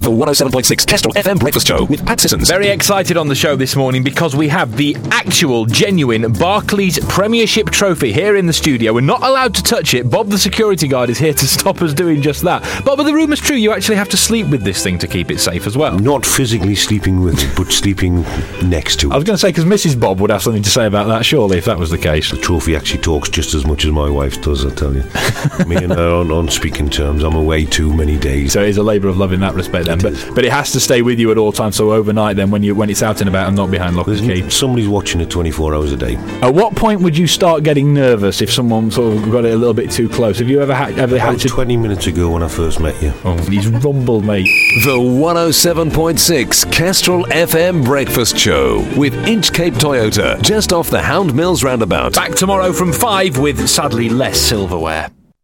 Old Kestrel FM promo